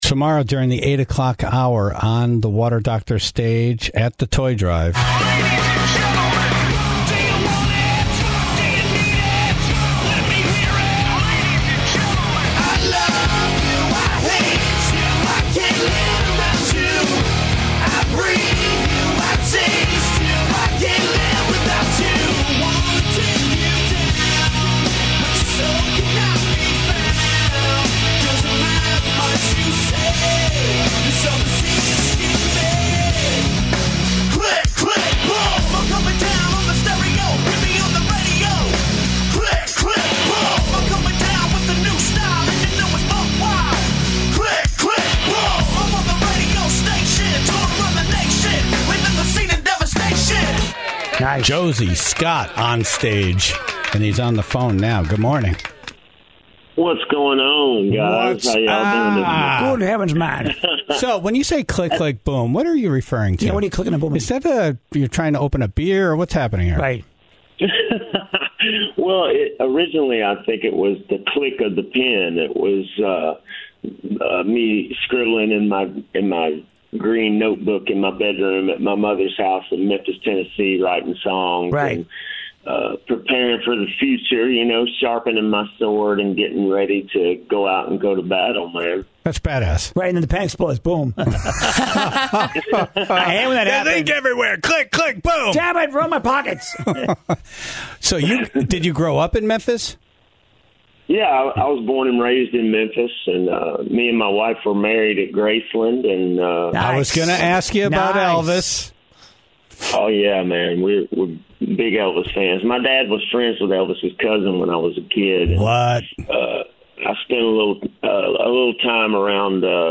Josey was on the phone this morning to talk about the start with Saliva, the three kings he grew up idolizing as a Memphis native, and his familial connection to Elvis.